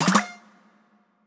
Countdown GO (2).wav